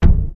Kick (Gone, Gone).wav